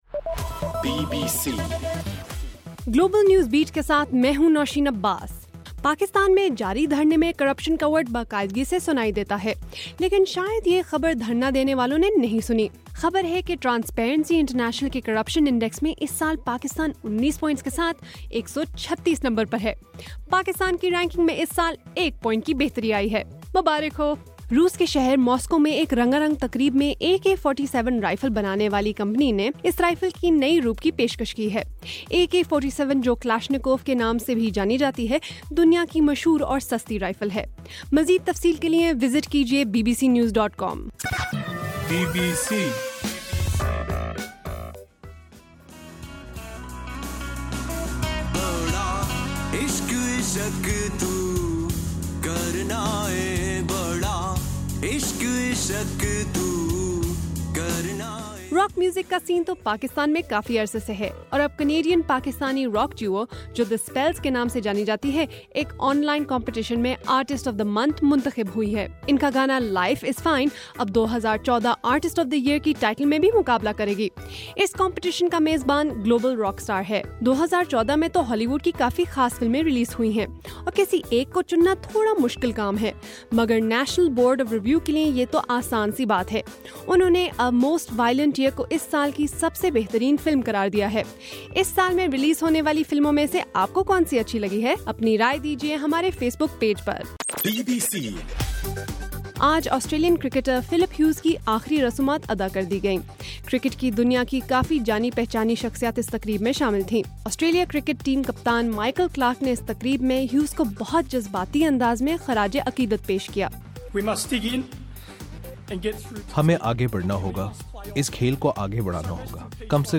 دسمبر4 : صبح 1 بجے کا گلوبل نیوز بیٹ بُلیٹن